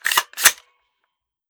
5.56 M4 Rifle - Cocking Slide 001.wav